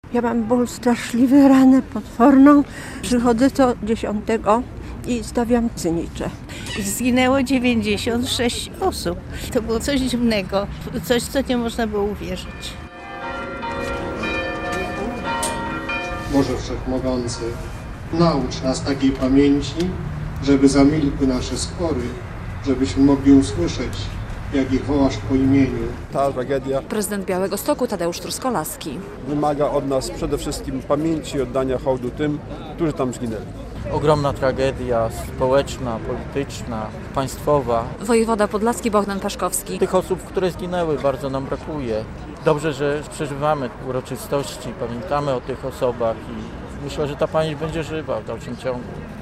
Białostockie obchody 6. rocznicy katastrofy smoleńskiej - relacja